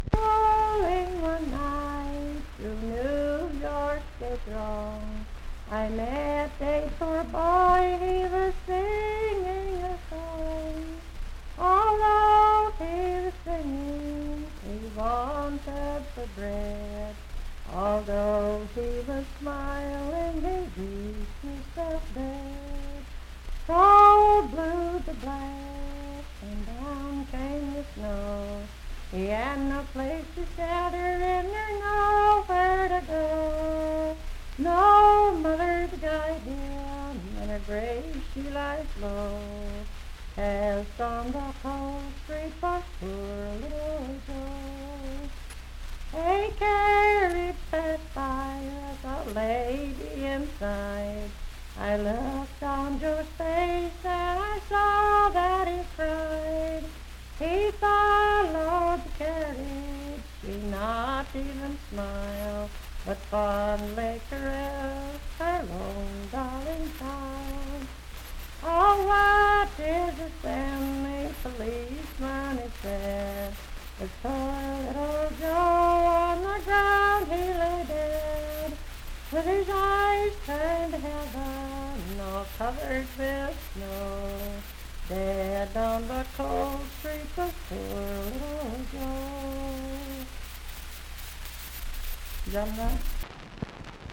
Unaccompanied vocal music
in Uffington, W.V..
Verse-refrain 4(4).
Voice (sung)